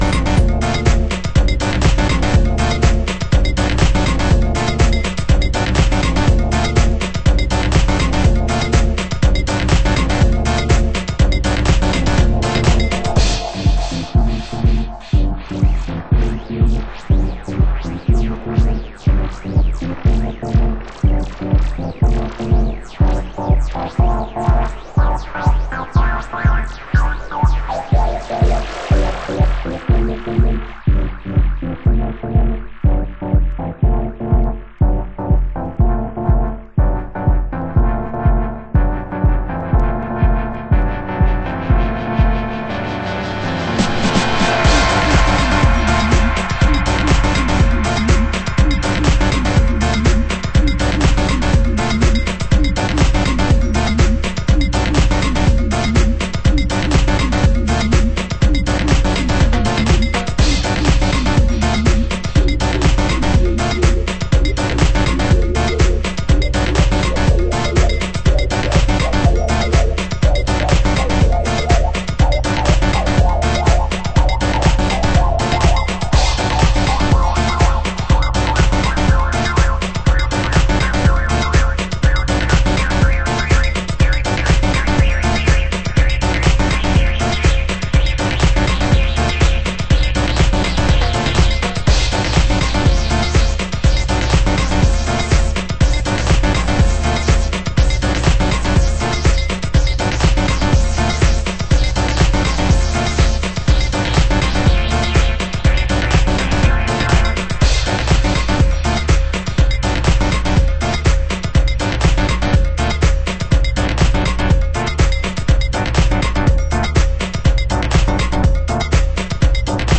盤質：少しチリパチノイズ有/A1終盤に部分的な傷 有　　ジャケ：白無地のインナースリーブのみ